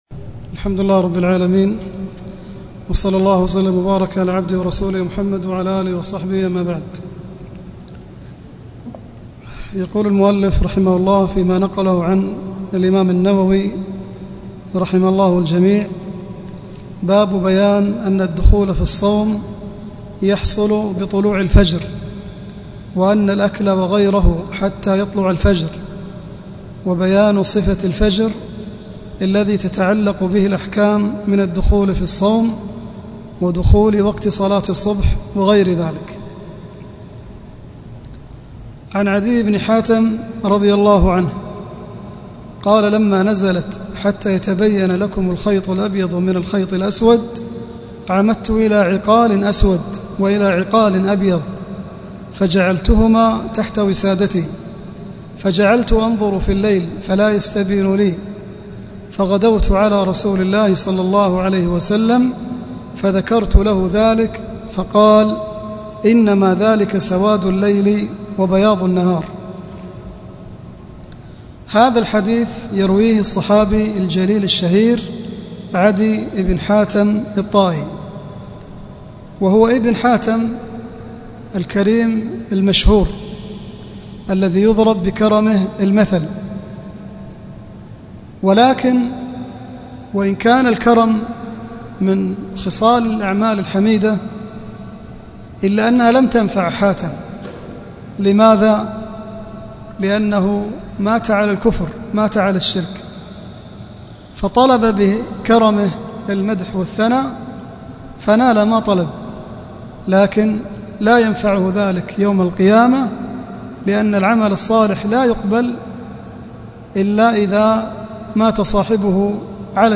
الدرس-الرابع-1.mp3